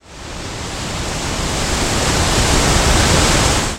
waterfall.mp3